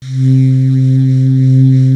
55af-sax01-C2.wav